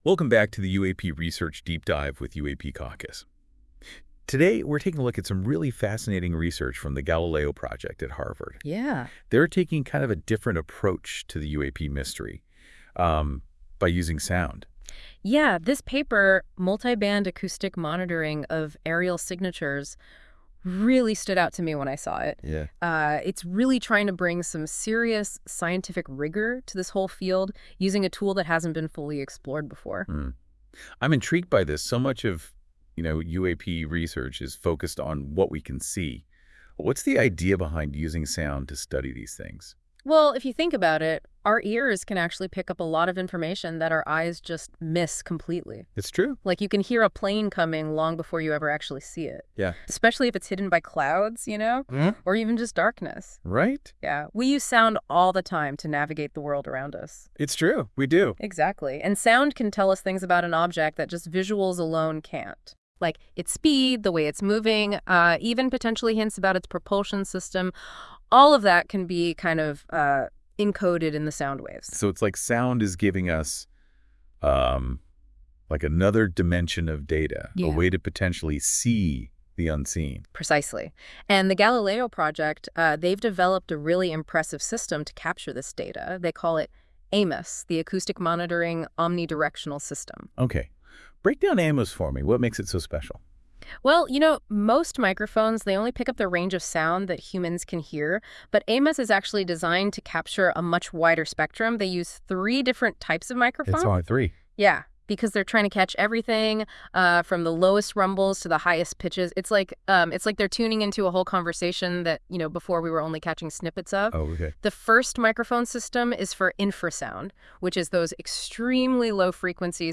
This AI-generated audio may not fully capture the research's complexity.
Audio Summary